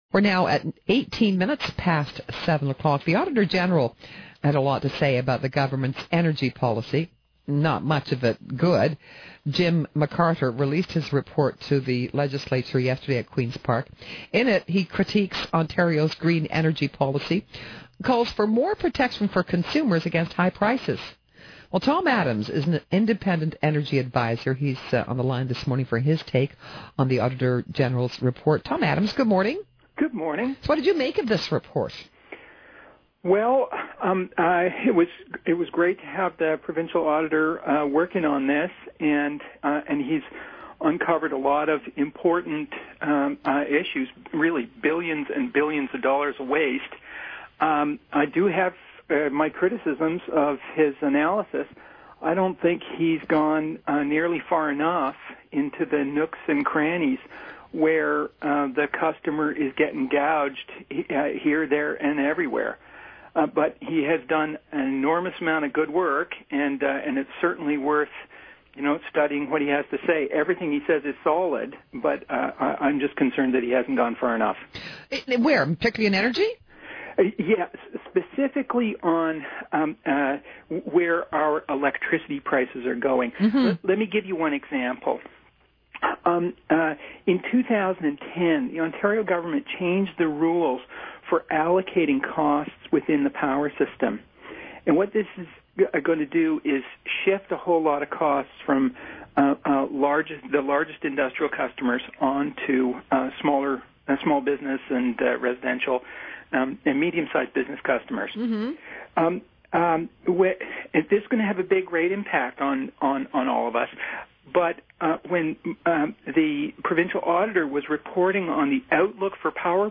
CBC_SUPERIORMORNING_7_18AM_DECEMBER6_2011_THUNDERBAY_7_46MIN is a podcast from this morning on CBC radio in Thunderbay.